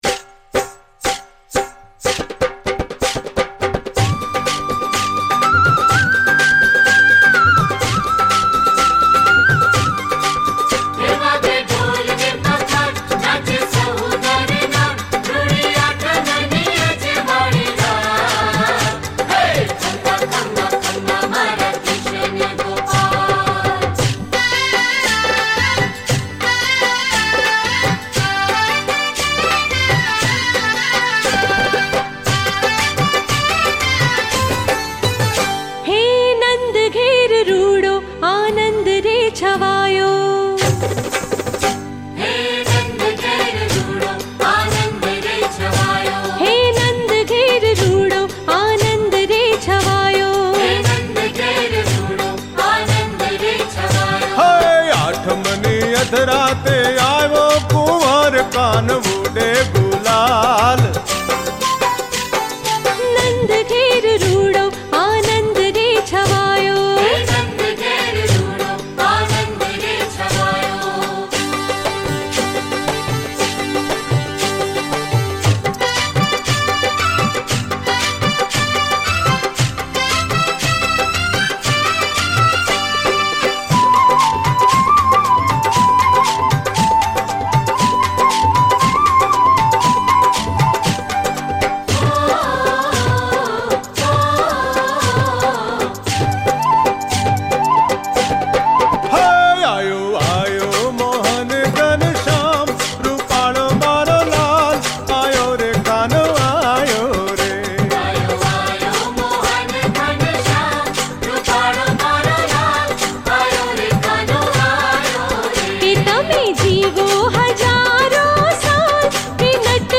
Gujarati Song
Original Motion Picture Soundtrack